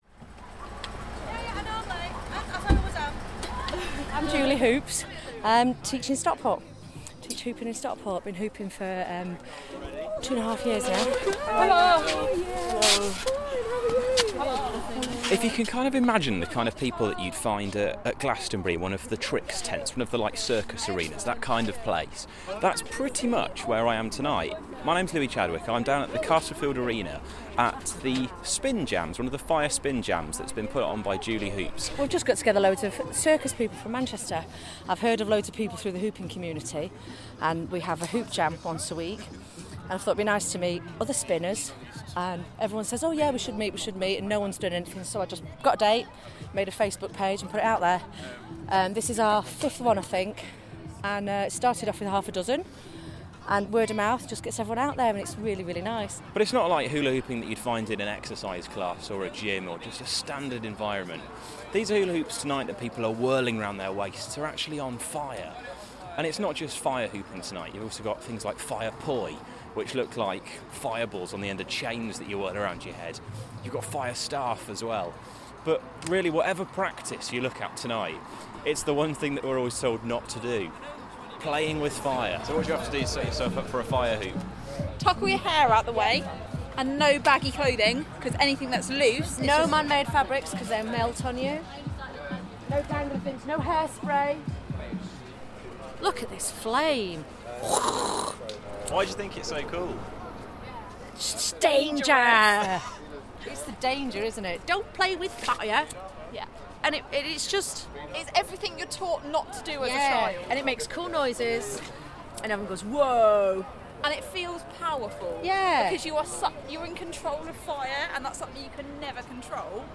This feature received a verbal commendation at the awards speech for the Charles Parker Prize 2013, acknowledging student radio feature making.